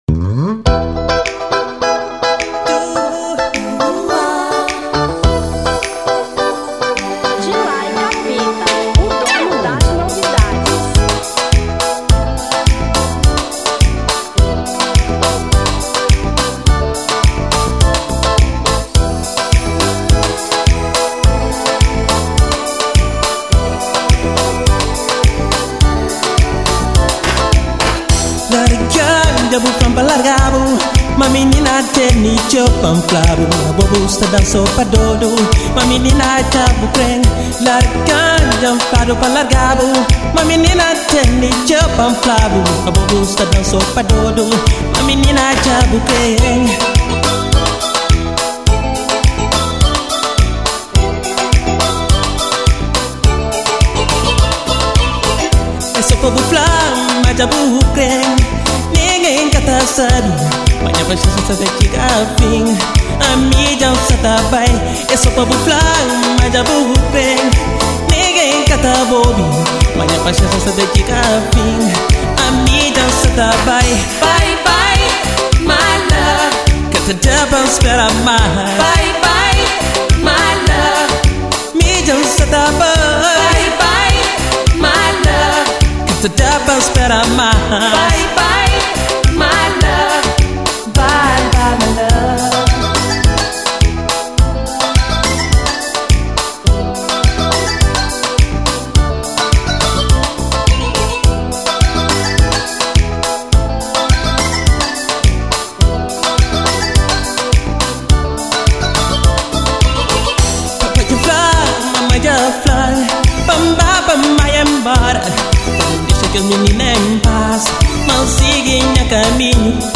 Kizomba 1995